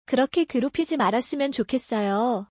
クロッケ クェロピジ マラッスミョン チョッケッソヨ